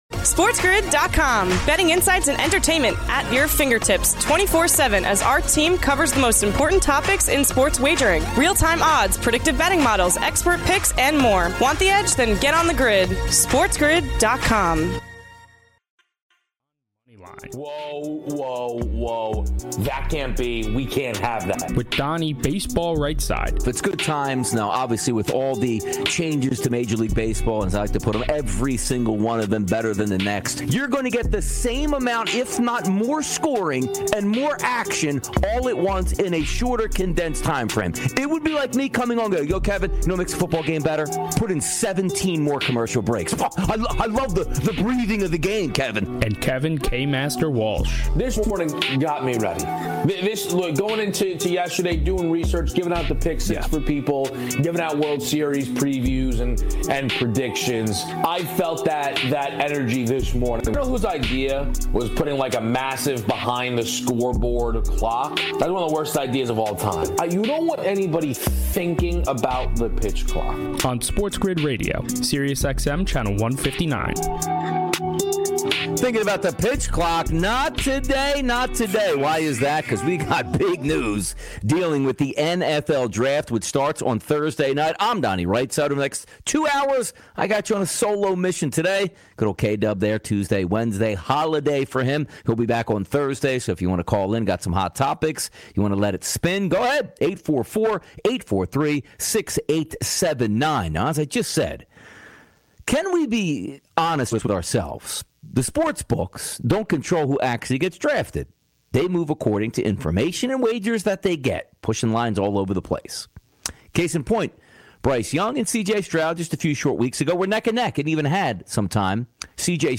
He wraps it up with MLB picks and best bets. Your calls, his picks and takes, and more!